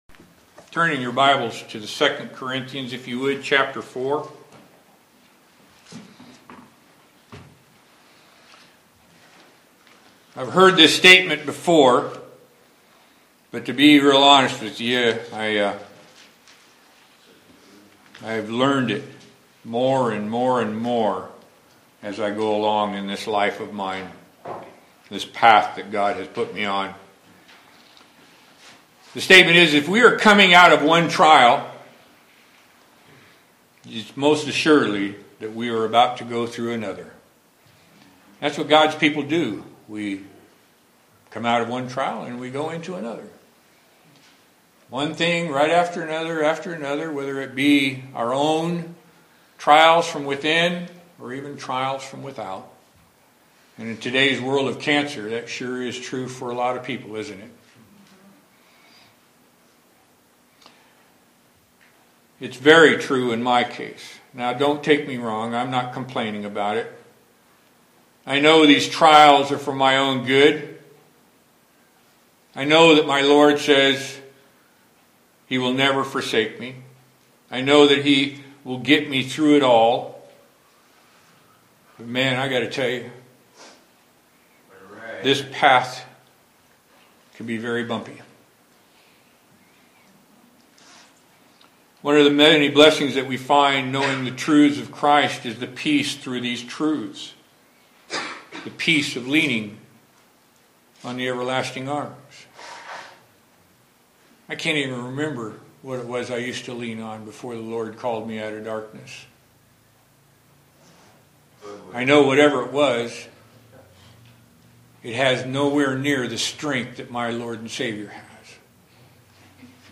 Renewed Day byDay | SermonAudio Broadcaster is Live View the Live Stream Share this sermon Disabled by adblocker Copy URL Copied!